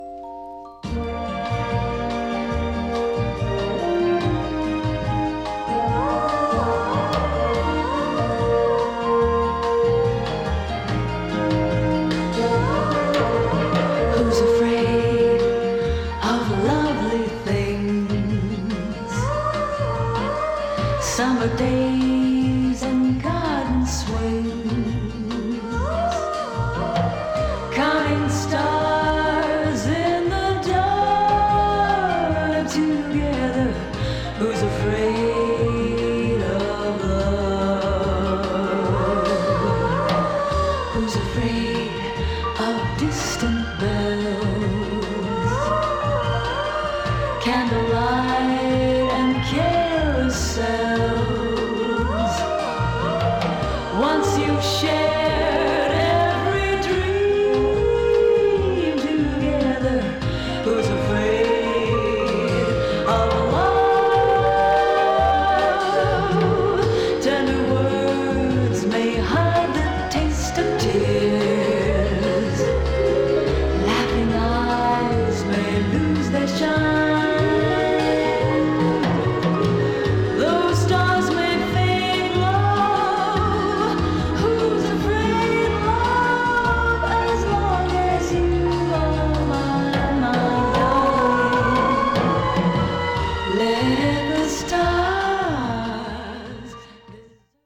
ボサノヴァ調ソフトロック・テイストな